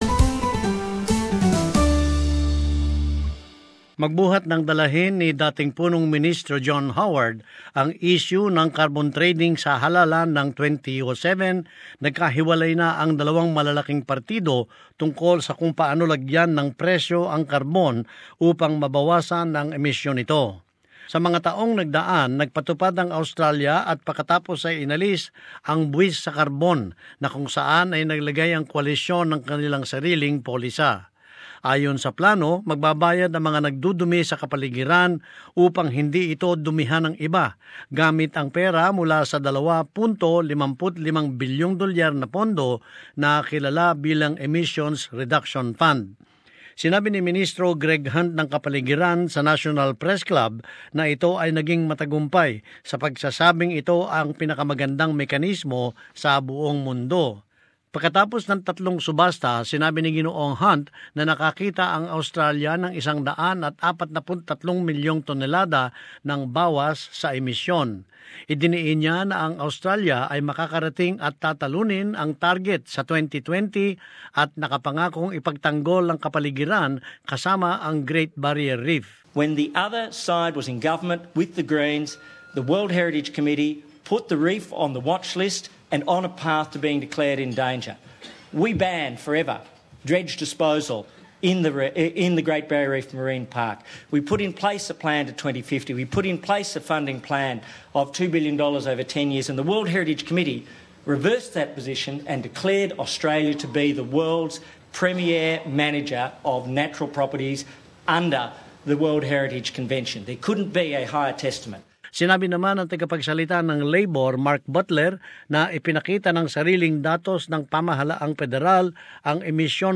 Climate policy debate at the National Press Club
The Environment Minister Greg Hunt and his Labor counterpart Mark Butler have gone head to head at the National Press Club over the slashing of carbon emissions.